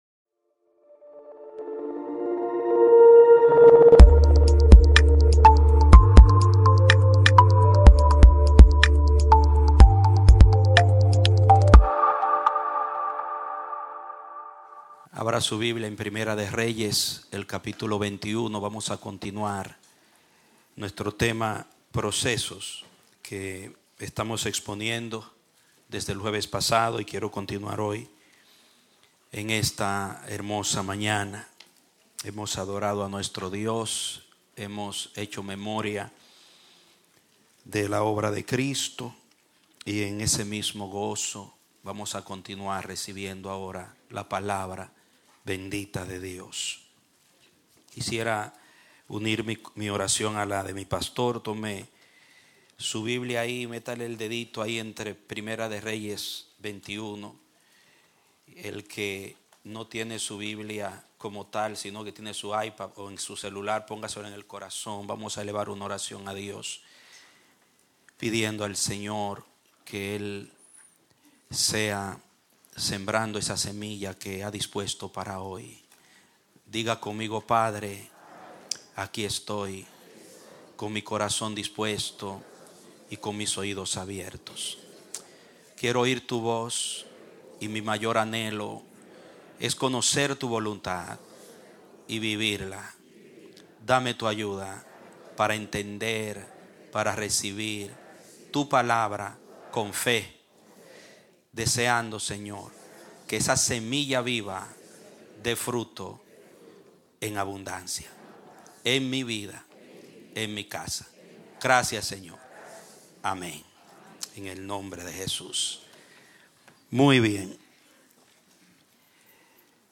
Un mensaje de la serie "Procesos ."